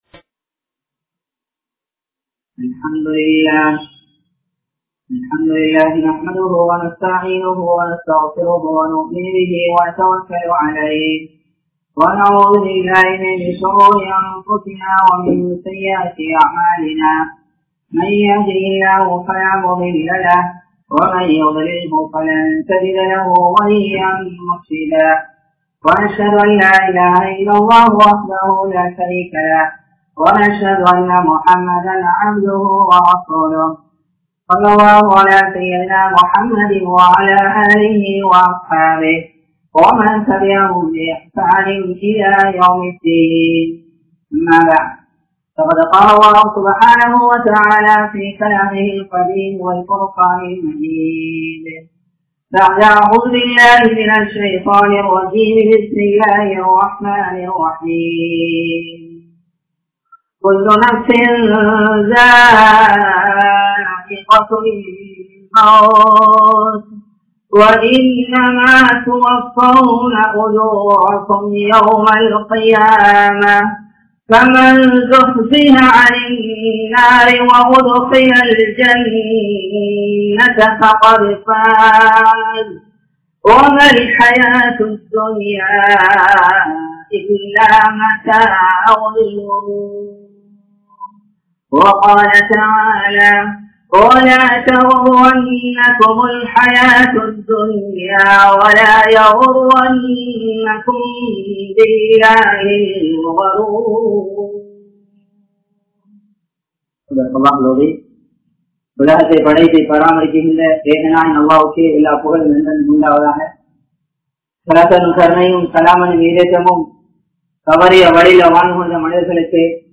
Allah`vai Santhikka Neengal Thayaaraa? (அல்லாஹ்வை சந்திக்க நீங்கள் தயாரா?) | Audio Bayans | All Ceylon Muslim Youth Community | Addalaichenai
Kawdana Road Jumua Masjidh